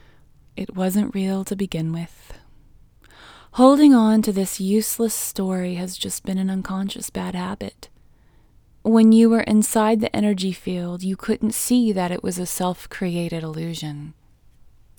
OUT Technique Female English 29